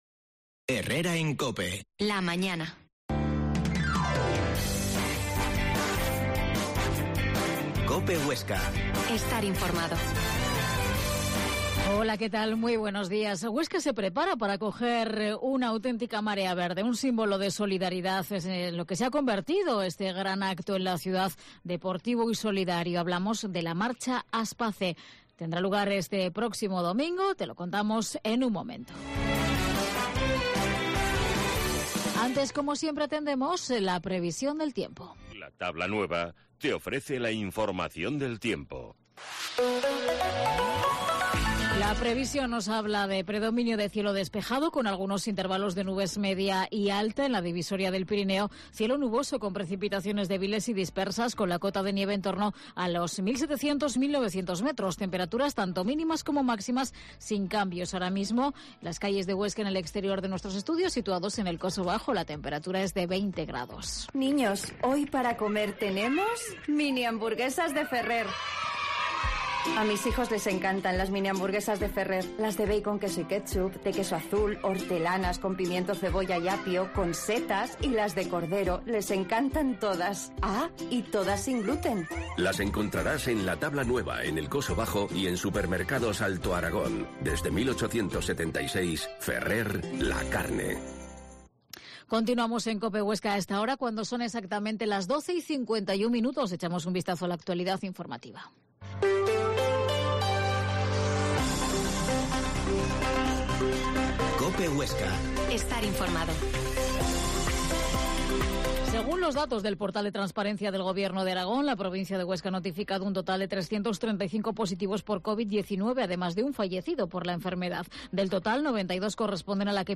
La Mañana en COPE Huesca - Informativo local Herrera en Cope Huesca 12,50h.